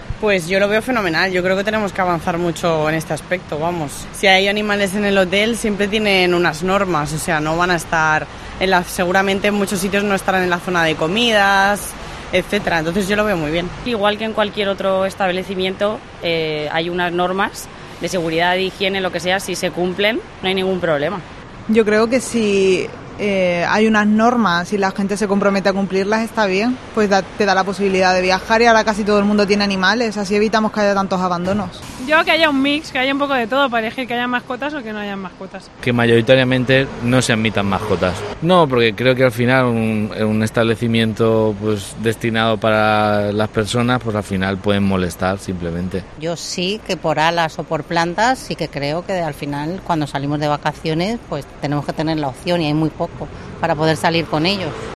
Mientras tanto, COPE Alicante ha sacado hoy sus micrófonos a la calle para preguntar a los ciudadanos si les parece bien la medida y hay división de opiniones.